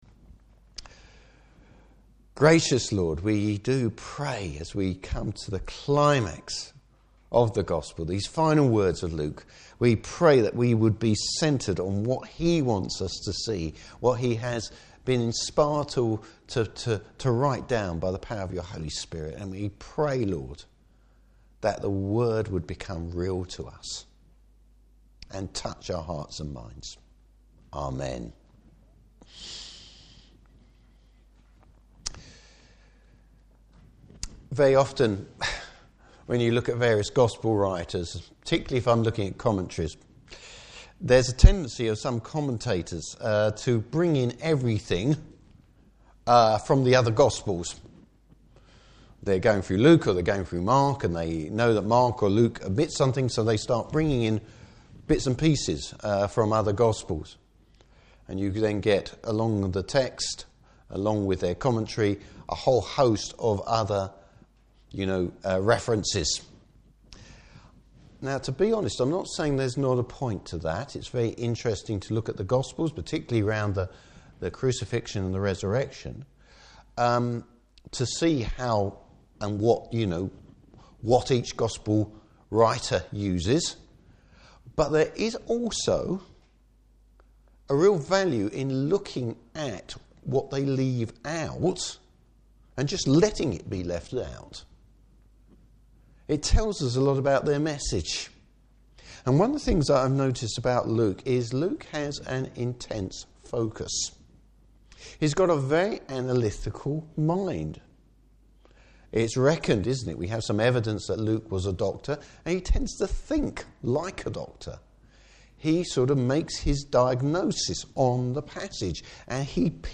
Service Type: Morning Service Jesus’ great commission for worldwide mission.